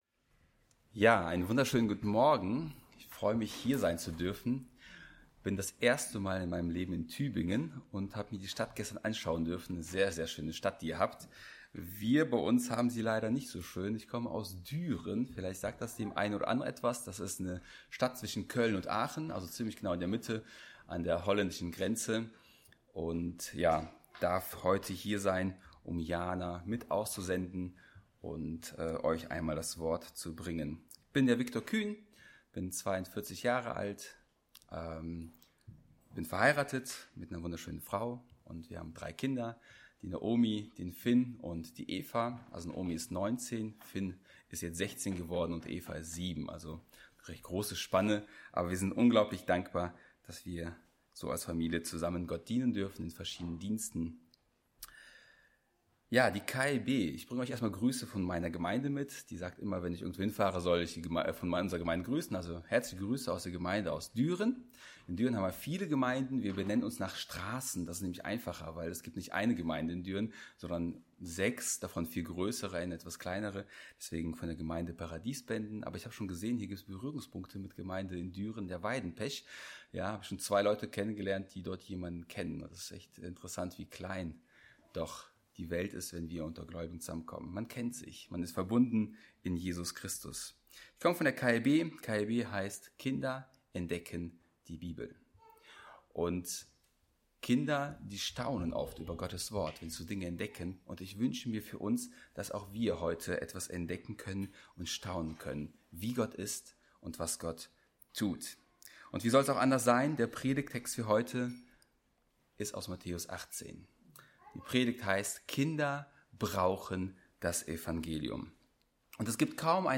Aussendungsgottesdienst